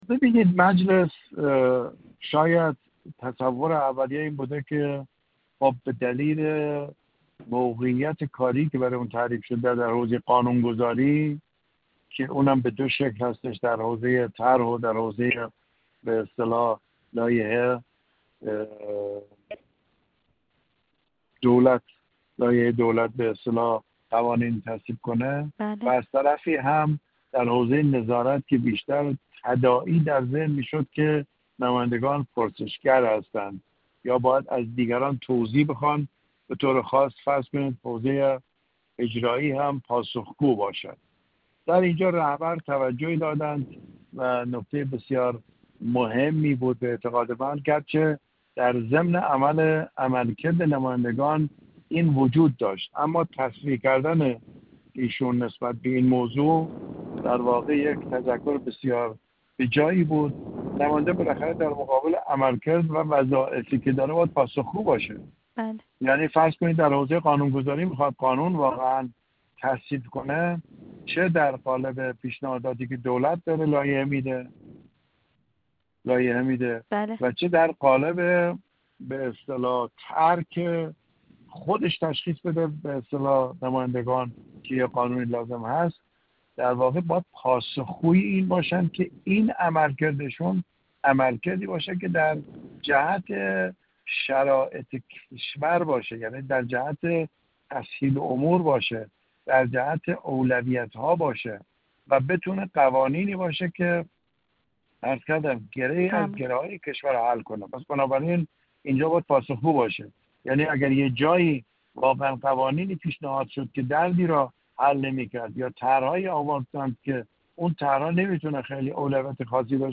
حجت‌الاسلام والمسلمین وحید احمدی، عضو کمیسیون امنیت ملی و سیاست خارجی
گفت‌وگو